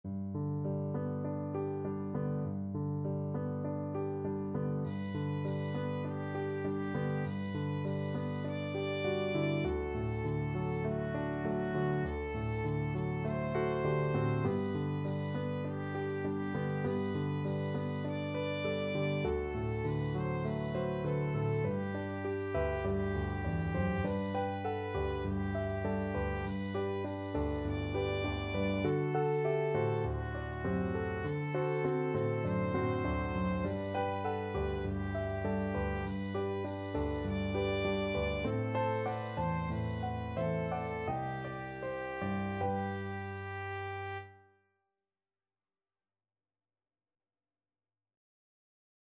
Violin
Traditional Music of unknown author.
4/4 (View more 4/4 Music)
G major (Sounding Pitch) (View more G major Music for Violin )
Gently Flowing = c.100